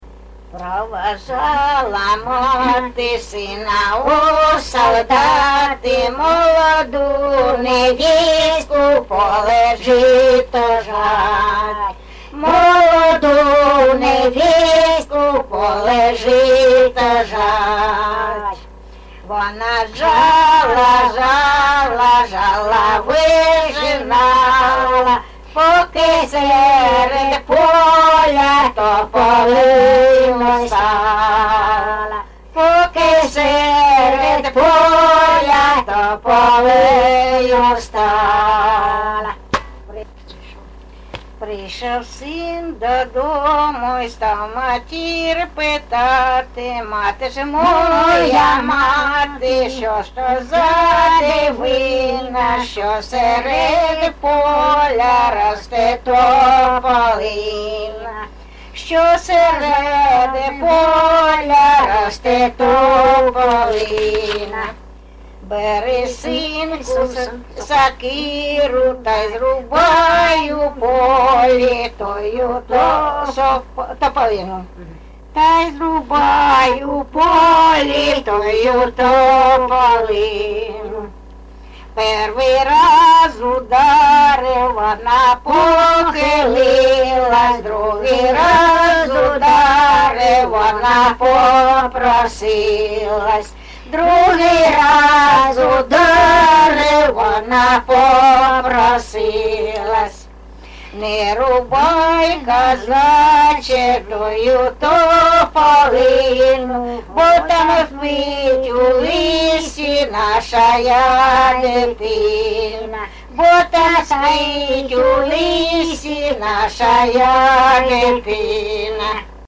ЖанрБалади
Місце записус. Закітне, Краснолиманський (Краматорський) район, Донецька обл., Україна, Слобожанщина